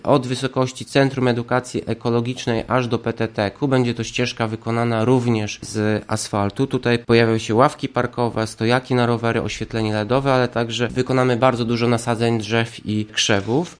prezydent Ełku Tomasz Andrukiewicz